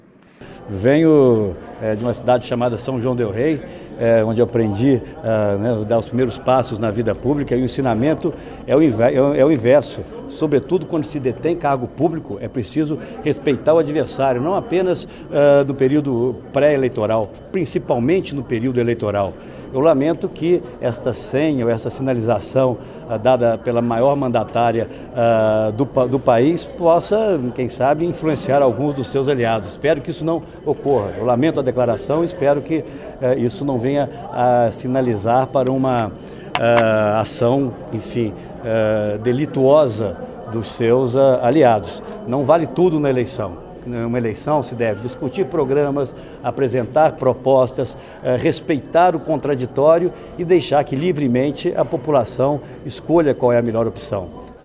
Fala do senador Aécio Neves